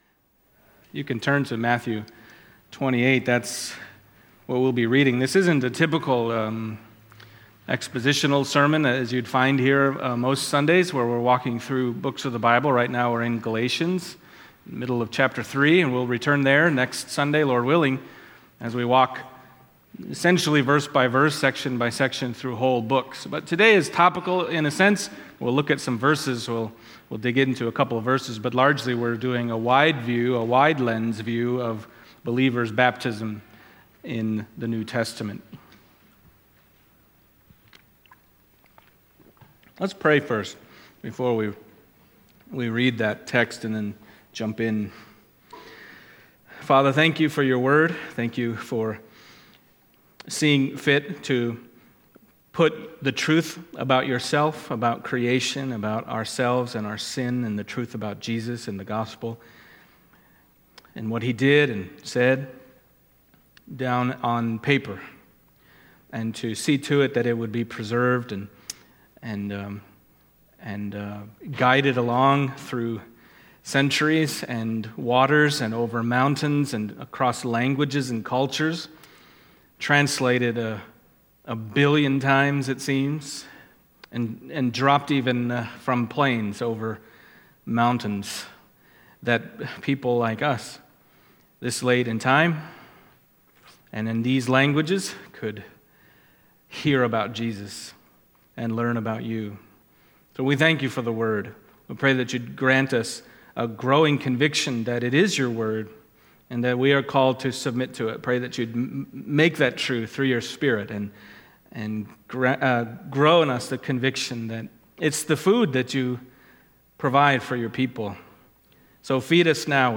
Romans 6:3-7 Service Type: Sunday Morning Matthew 28:18-20